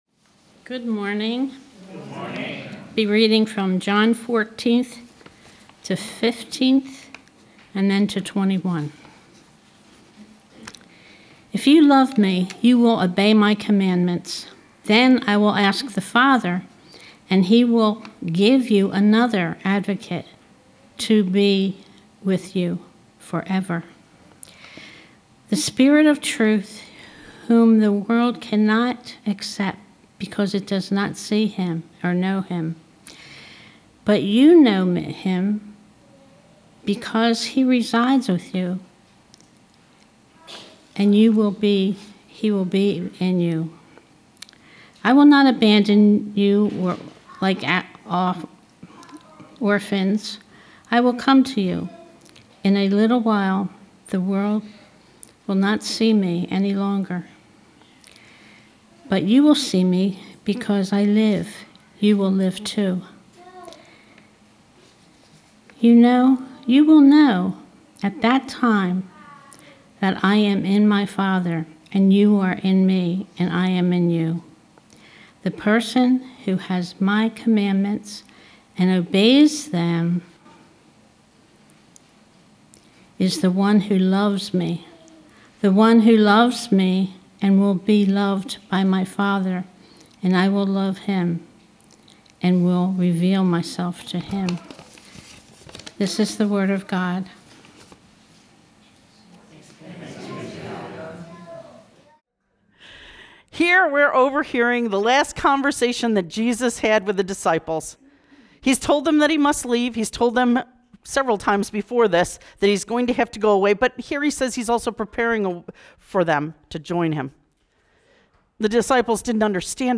August 24, 2025 Sermon Audio